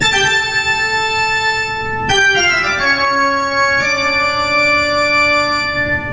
Halloween Organ: Instant Play Sound Effect Button